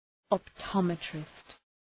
Προφορά
{ɒp’tɒmıtrıst}